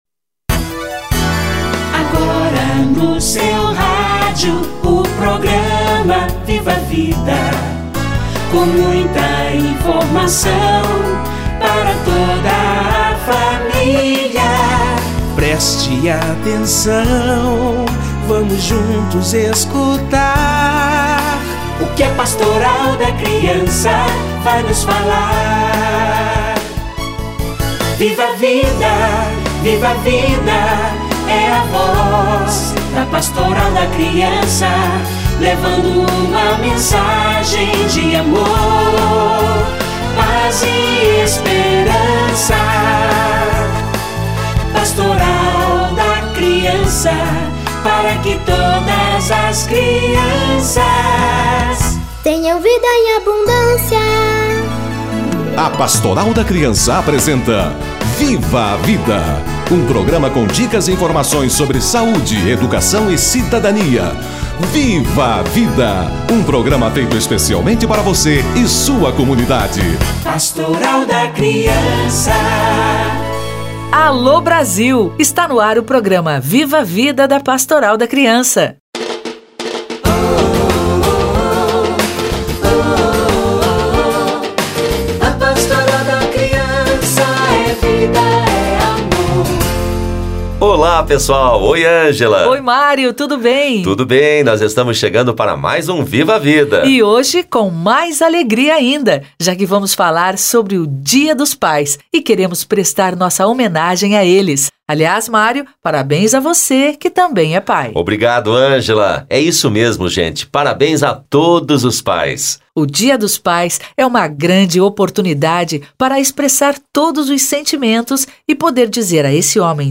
Dia dos pais - Entrevista